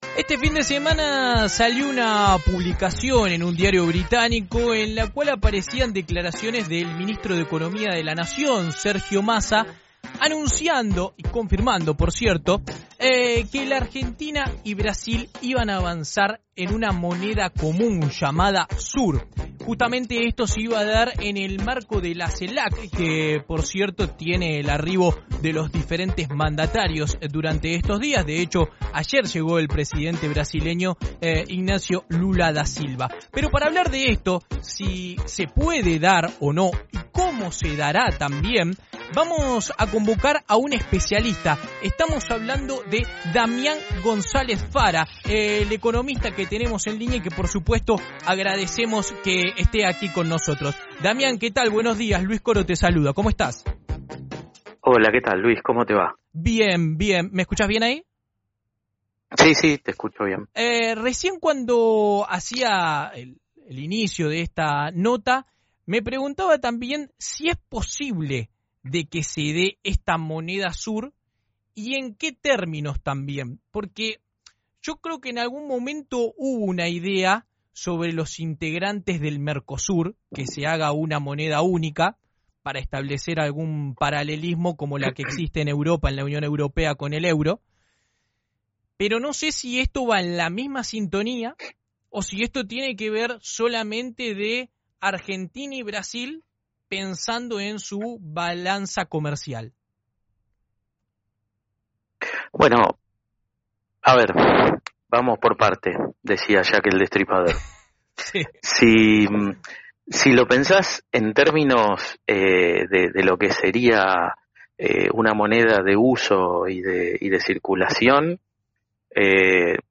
en diálogo con FRECUENCIA ZERO